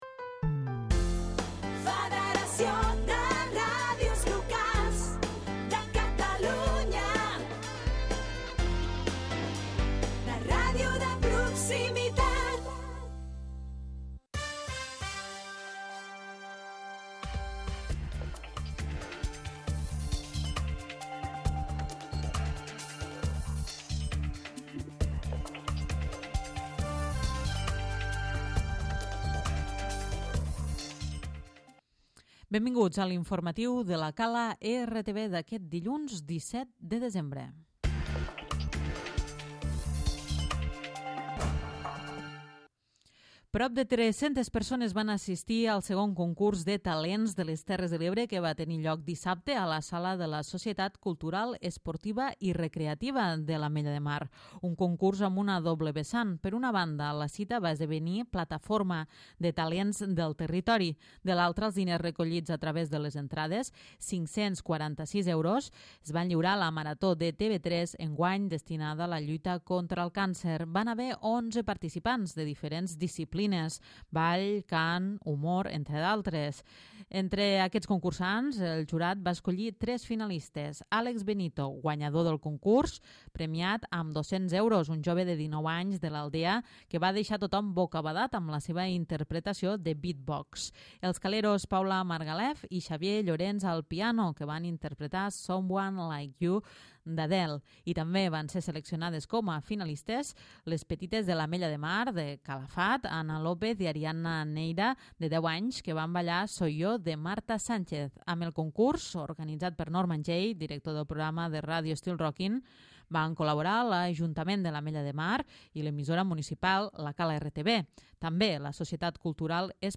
Butlletí informativa